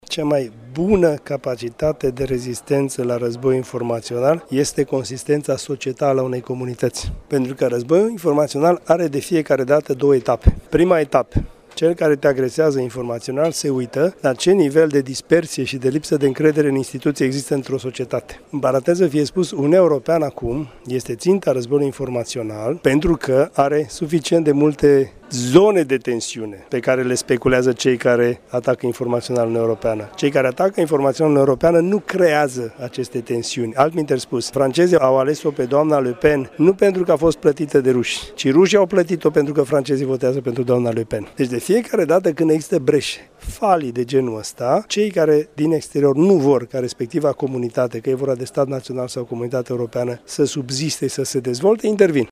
La Iași, s-a desfășurat, astăzi, conferința cu tema „Provocări de securitate în Sud-Estul Europei”, organizată de universităţile Alexandru Ioan Cuza şi Tehnică Gheorghe Asachi şi de Organizația New Strategy Center.
Analistul Dan Dungaciu a spus, în timpul conferinței că Uniunea Europeană a fost şi este ţinta unui război informaţional. Rusia nu creează zone de tensiune ci doar le speculează pe cele deja existente: